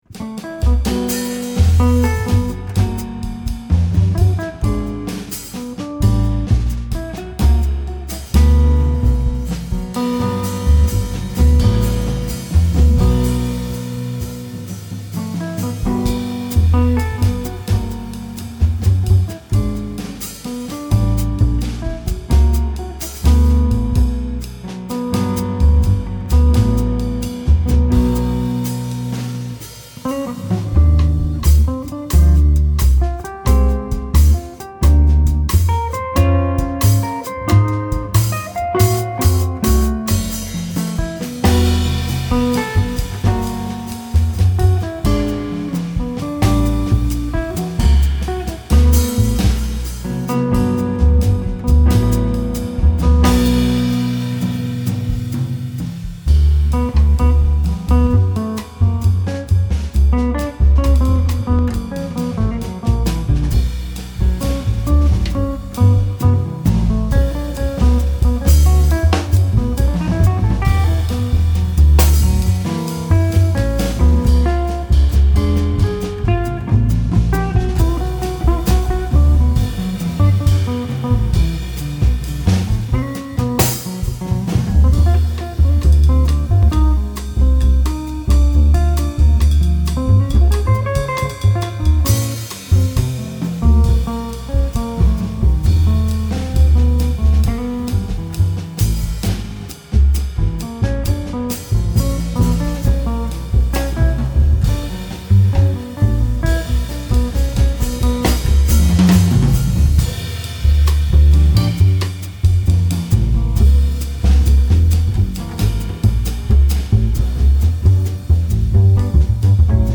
FILE: Jazz